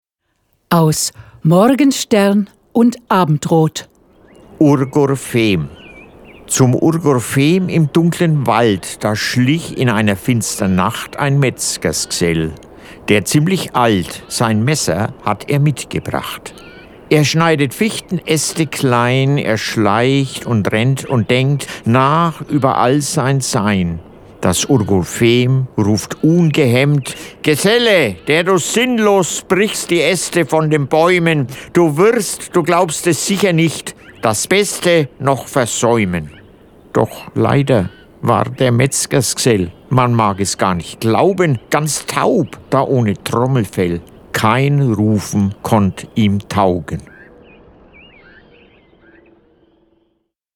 Diese kurzen Hörspiele (max. 10 Minuten) sind von Mitgliedern des Blumenordens, die in den zwanziger Jahren dieses Jahrhunderts leben oder noch gelebt haben, geschrieben und gesprochen, gespielt oder musikalisch vorgetragen.